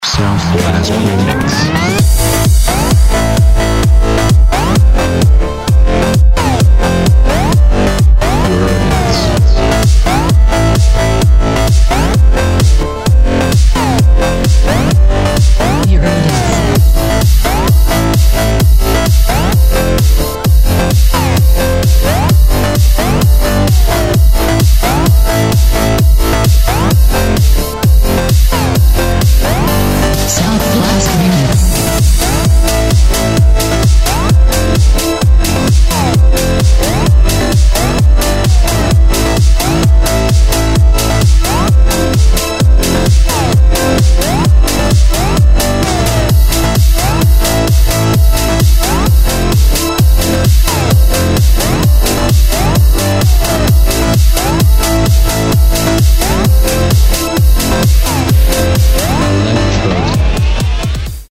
Список файлов рубрики Мр3 | House